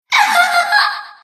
Enemy_Voice_Destroyer_Ra-Class_Delta_Attack.mp3